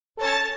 Goku-Drip-Sound-Effect.mp3